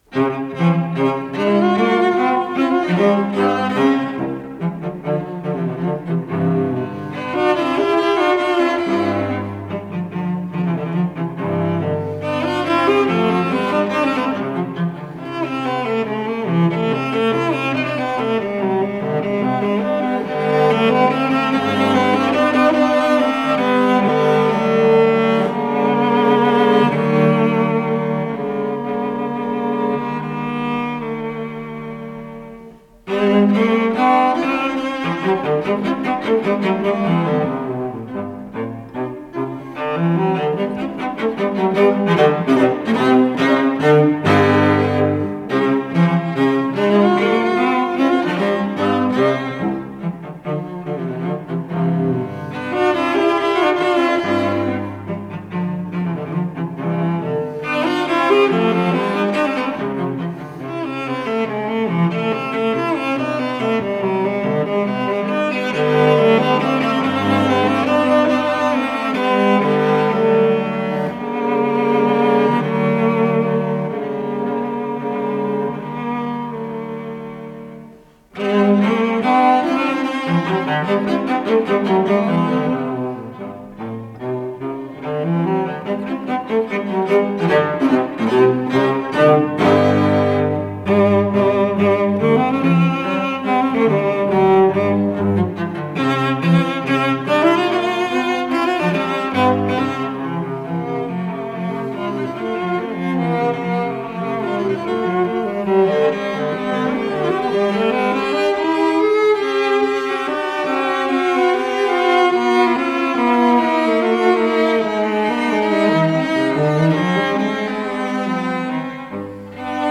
с профессиональной магнитной ленты
Аллегро
ВариантДубль моно